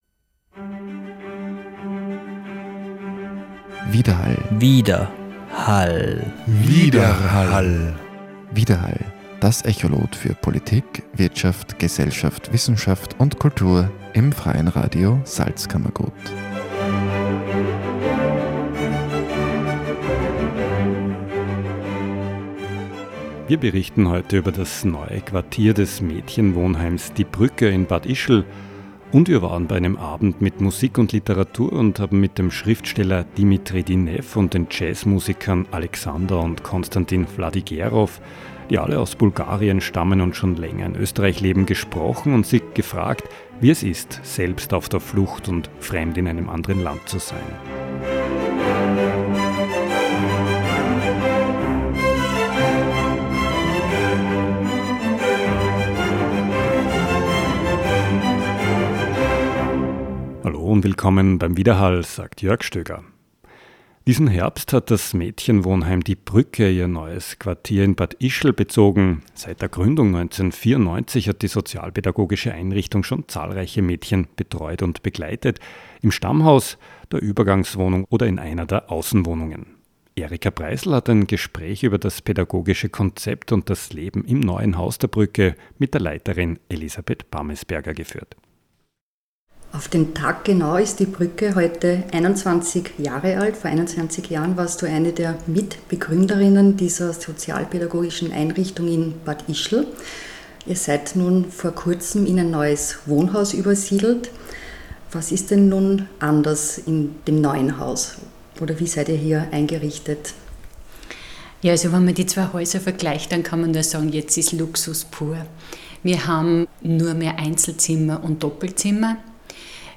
Der Beitrag bringt Ausschnitte aus dem Abend. Im anschließenden Gespräch schildern die Künstler unter anderem ihre Eindrücke zur derzeitigen Flüchtlingssituation und ihre Erinnerungen an die eigene Ankunft in Österreich.